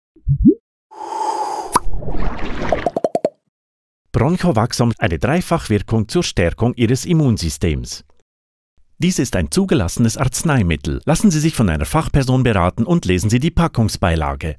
Werbung Hochdeutsch (CH)
Meistgebuchter Sprecher mit breitem Einsatzspektrum. Diverse Dialekte und Trickstimmen.